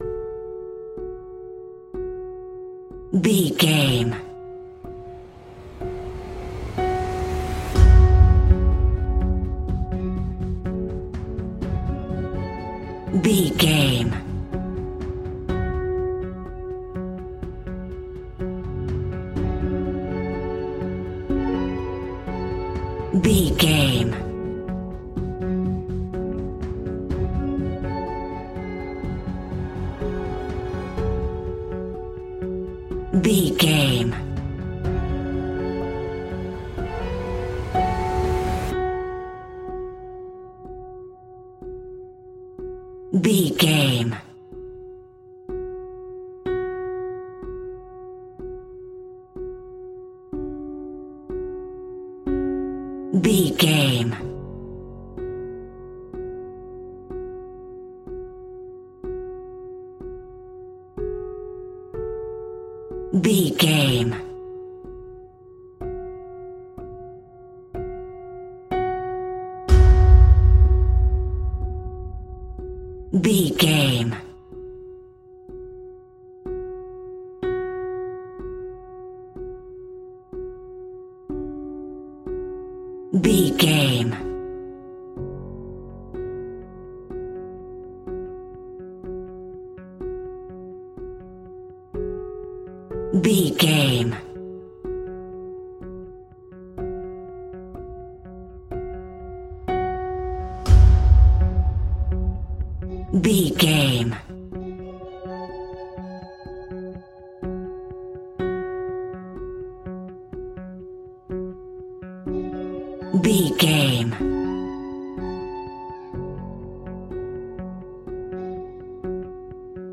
Ionian/Major
F♯
electronic
techno
trance
synths
industrial
drone
instrumentals